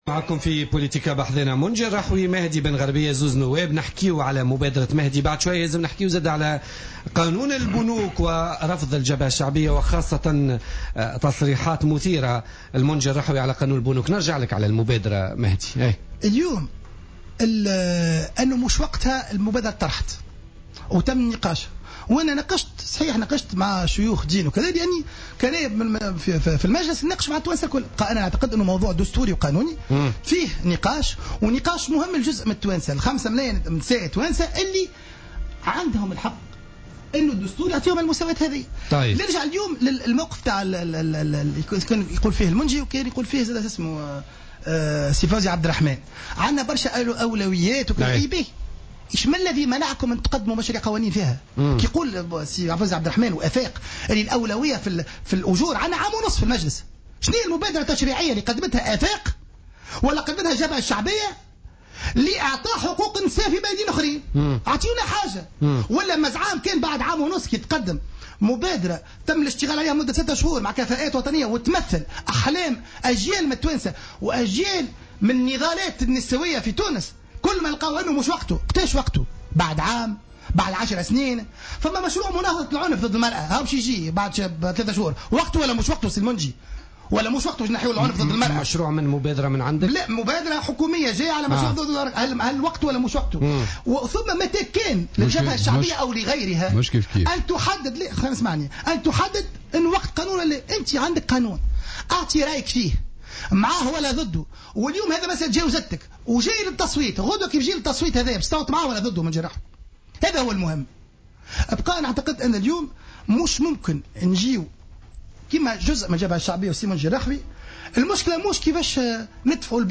وعلّق قائلا في نقاش حاد جمعه مع صاحب المبادرة باستوديو "الجوهرة أف أم" في برنامج بوليتيكا" وبالتعبير التونسي: المبادرة "ماهوش شاربها" في إشارة إلى صاحبها، موضحا أنه يفترض أن تأخذ مثل هذه المبادرات بالاعتبار عوامل متشابكة ثقافية منها واجتماعية.